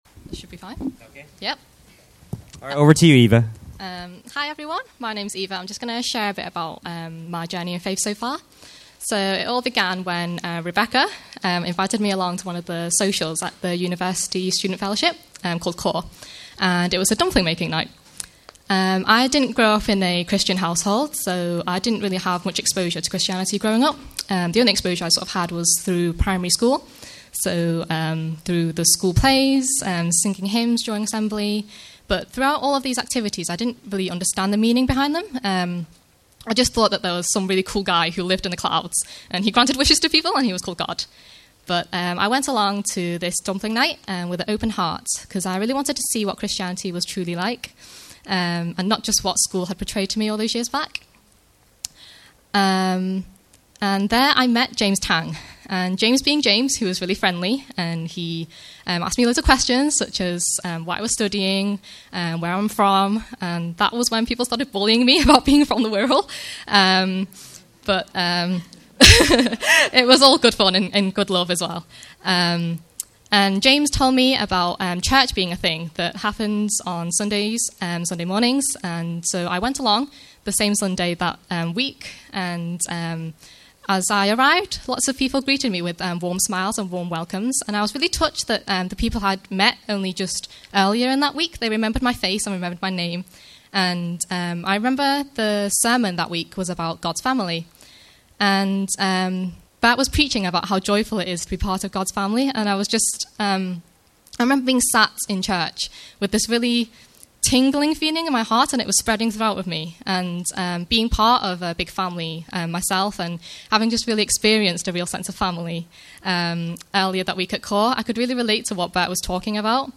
BCEC Easter Testimonies Omnibus